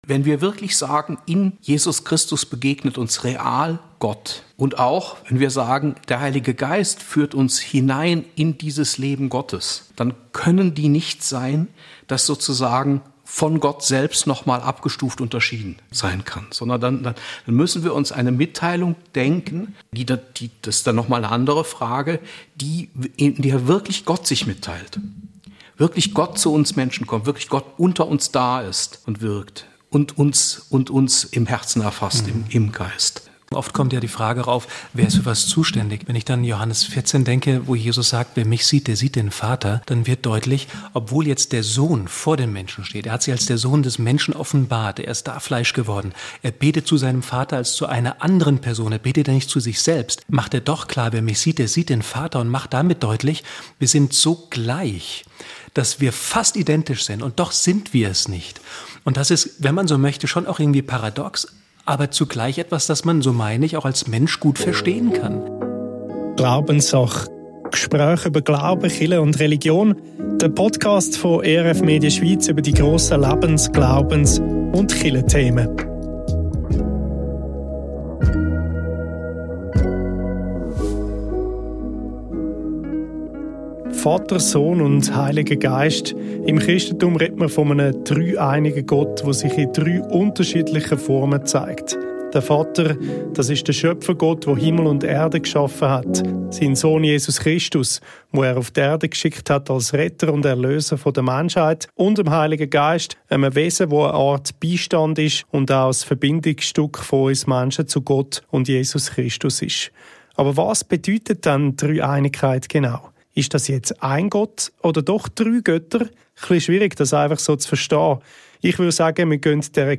Drei Menschen im Gespräch über die Dreieinigkeit Gottes.